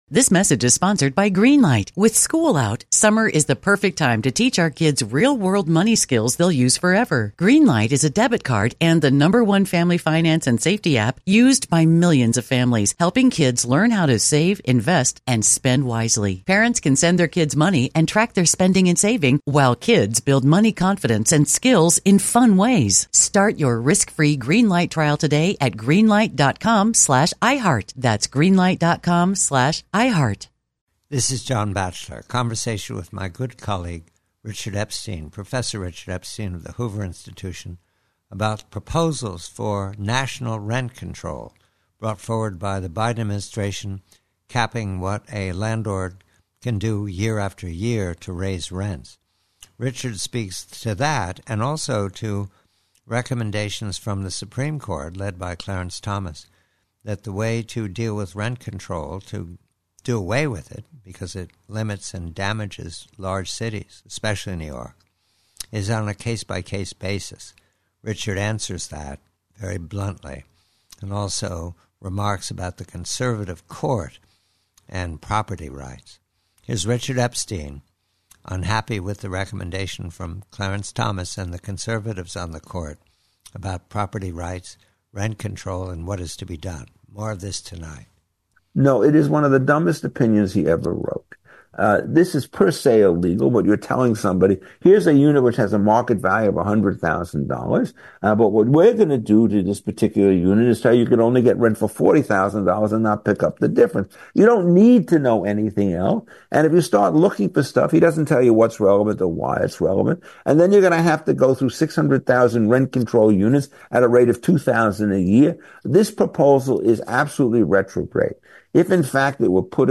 PREVIEW: RENT CONTROL: BIDEN: Conversation with colleague Richard Epstein of Hoover re: the rent control reform recommended by Associate Justice Clarence Thomas as well as the general opinion of the Conservatives in the Court on property rights.